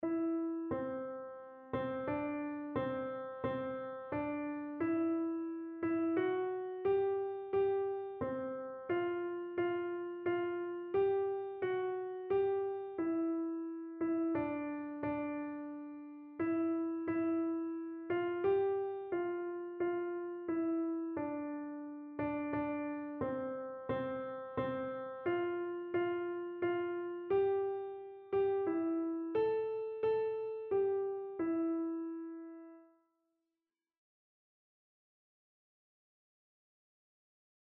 Einzelstimmen (Unisono)